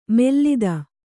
♪ mellida